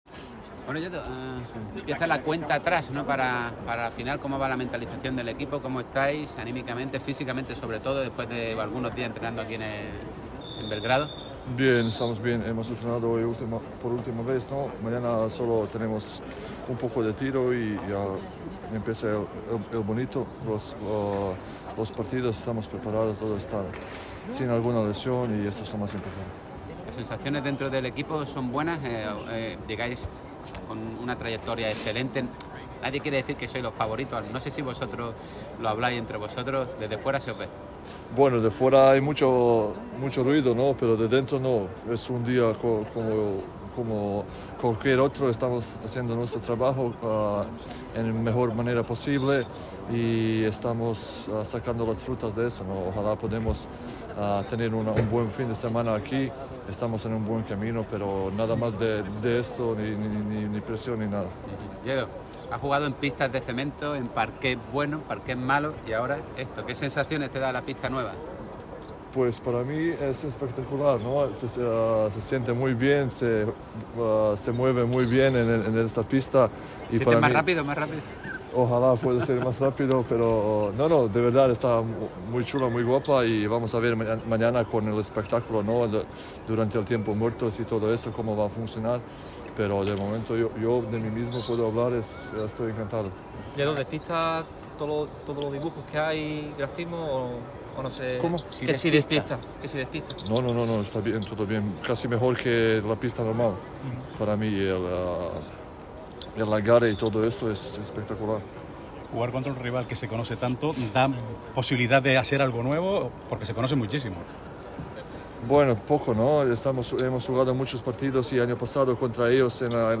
Nihad Djedovic, alero-escolta del Unicaja, asegura que el enfrentamiento ante el UCAM Murcia lo ganará el equipo que cometa menos errores. El bosnio habló para los medios en la previa de la semifinal ante los de Sito Alonso en la Final Four de la BCL en Belgrado.